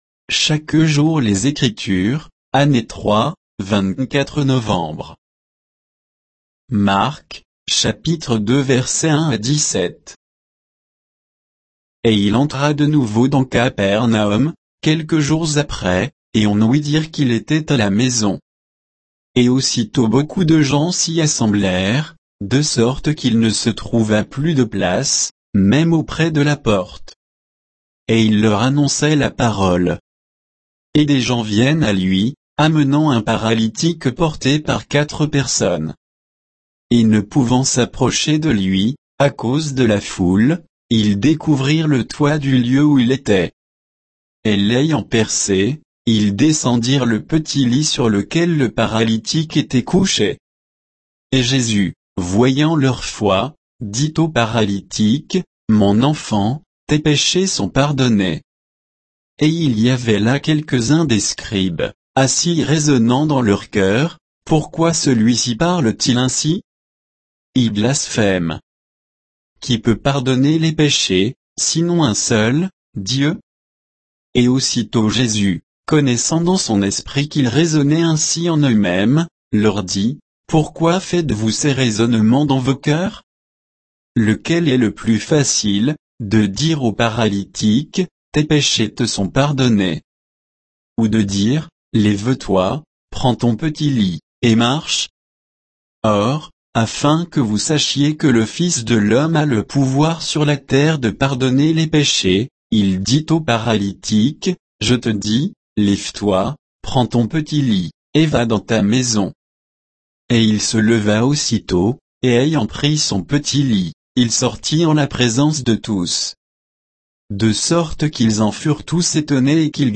Méditation quoditienne de Chaque jour les Écritures sur Marc 2